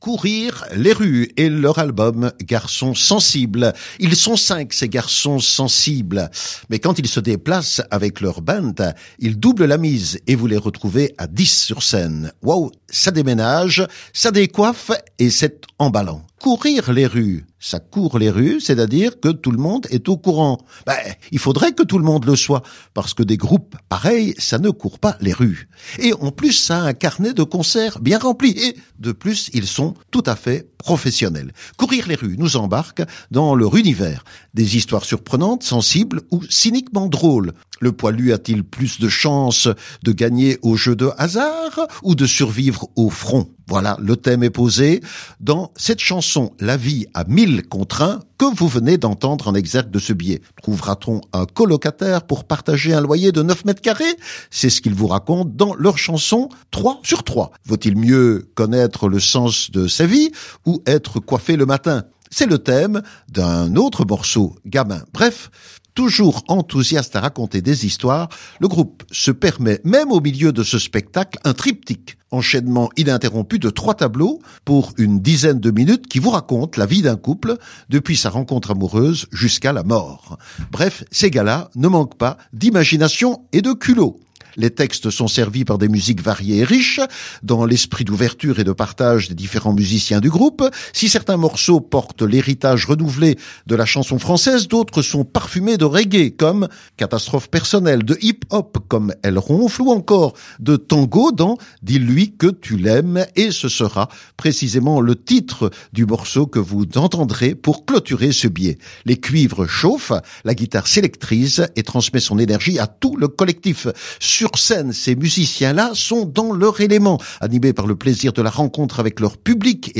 à la batterie et aux percussions